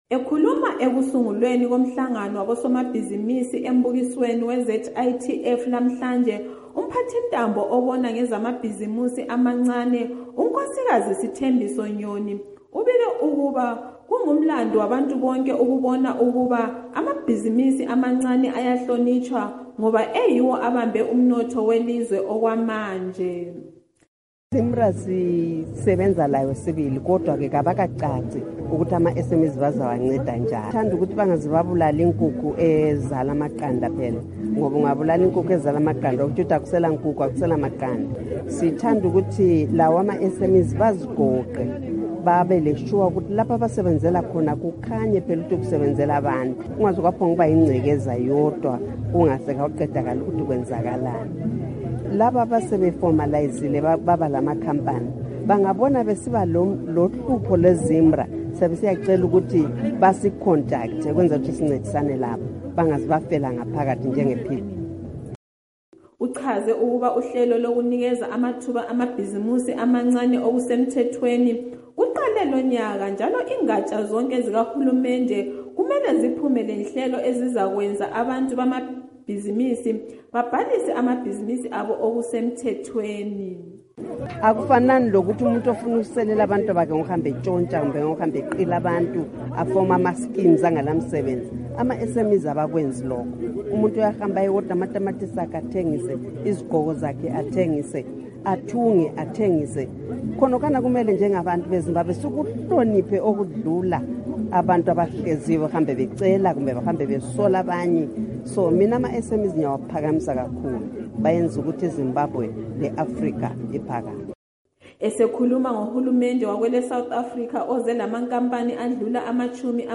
Zimbabwe VP Emmerson Mnangagwa addresses annual trade fair in Bulawayo and raises concern over country's inability to produce goods. As a result he says Zimbabwe has become a dumping ground and urged local industries to produce quality products so as to be competitive.